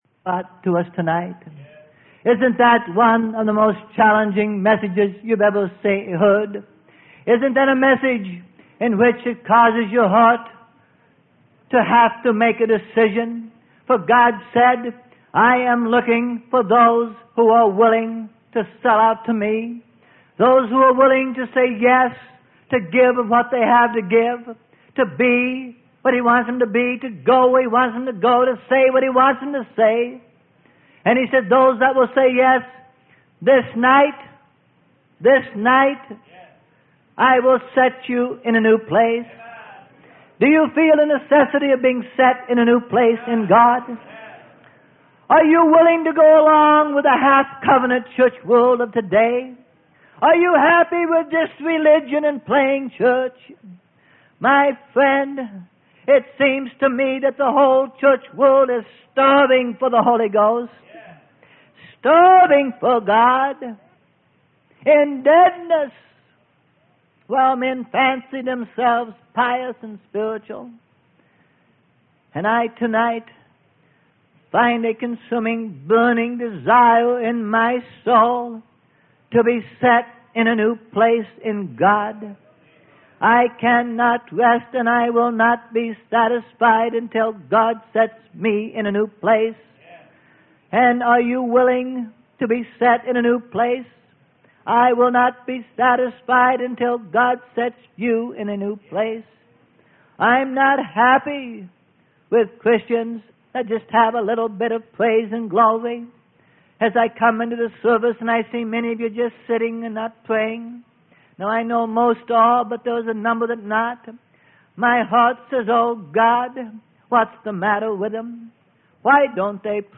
Sermon: Anointed For Service - Freely Given Online Library